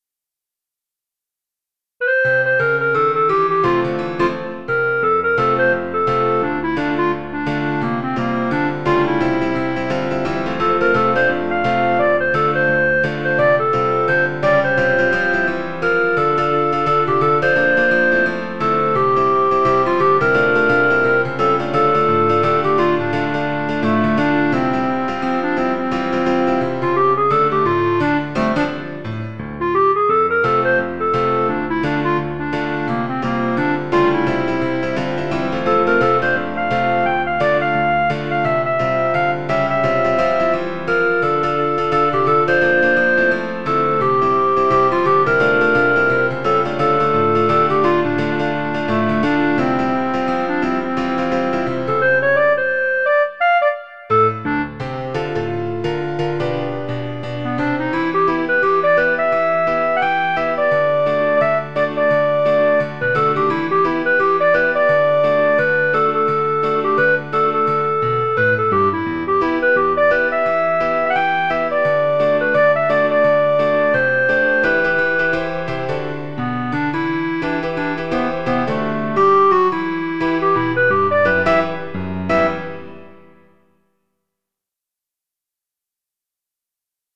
Title Little Bop IV Opus # 382 Year 2006 Duration 00:01:27 Self-Rating 4 Description The latest in the popular series. Written in under three hours. mp3 download wav download Files: mp3 wav Tags: Duet, Piano, Clarinet Plays: 1438 Likes: 0